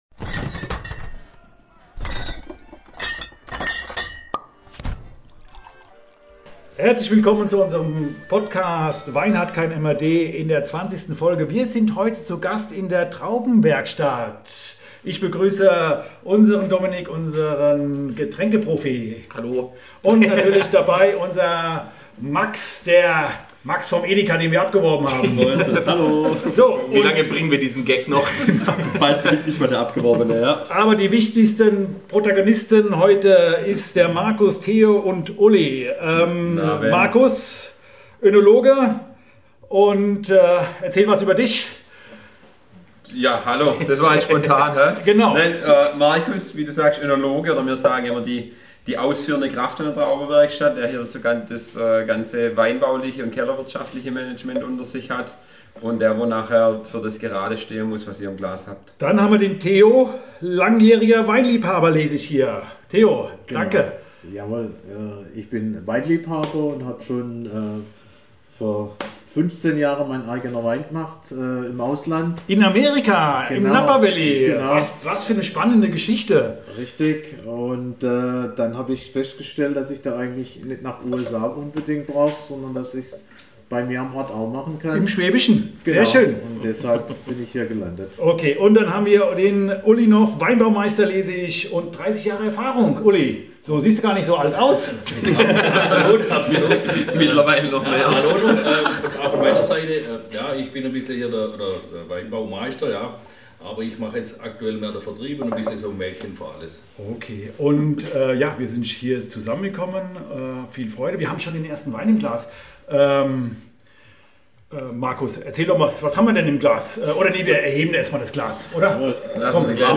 Einmal quer durch alle Rebsorten, alle Qualitäten und mit jeder Menge ehrlicher Eindrücke, Gelächter und Überraschungen. Aufgenommen haben wir direkt im Weinkeller – mega Atmosphäre, aber ja… es hallt ein bisschen. Dafür schon mal ein großes Sorry!